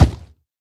assets / minecraft / sounds / mob / hoglin / step4.ogg